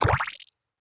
Special & Powerup (19).wav